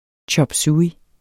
Udtale [ tjʌbˈsuːi ]